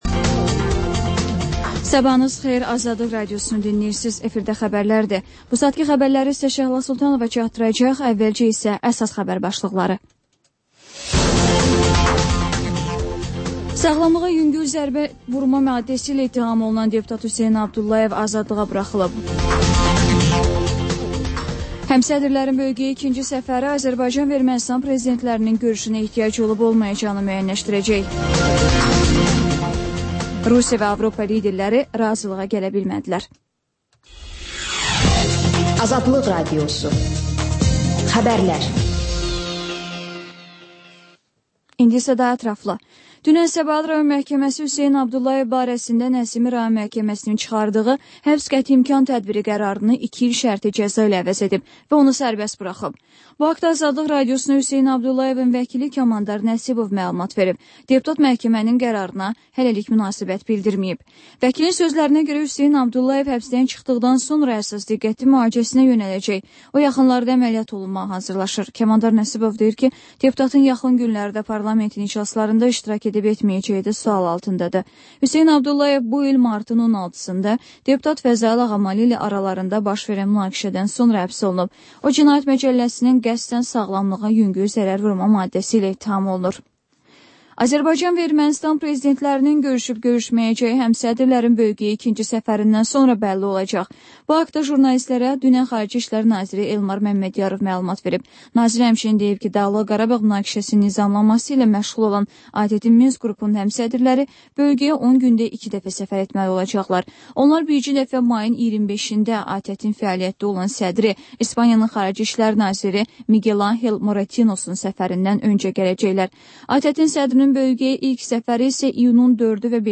Xəbərlər, ardınca XÜSUSİ REPORTAJ rubrikası: Ölkənin ictimai-siyasi həyatına dair müxbir araşdırmaları. Sonda isə TANINMIŞLAR verilişi: Ölkənin tanınmış simalarıyla söhbət